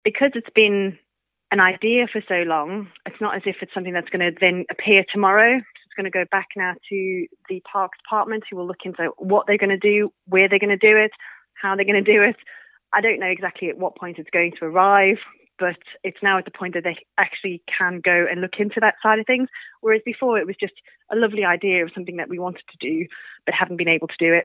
Mrs Wells says it'll will still be some time before the new facilities are actually built: